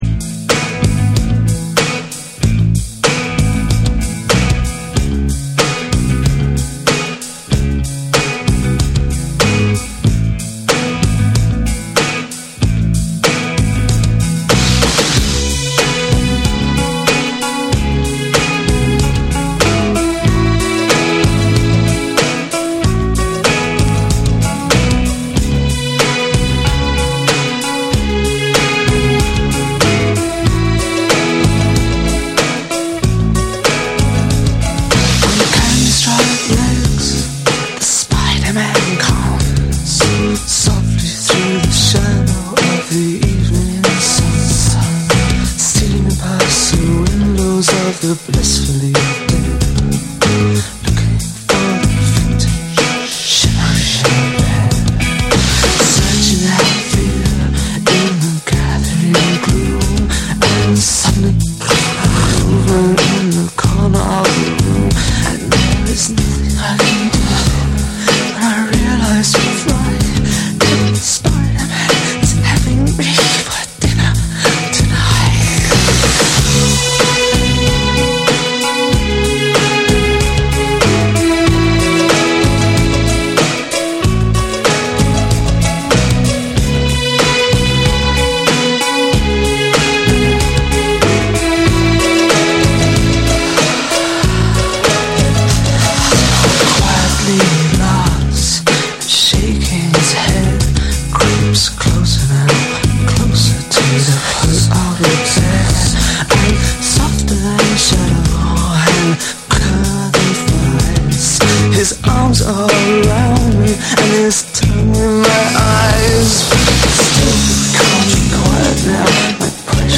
ロック、ダブ、ポストパンク、スカ、ヒップホップなどを横断したセレクションで、ダークでスモーキーな世界観を構築。
BREAKBEATS / NEW WAVE & ROCK / REGGAE & DUB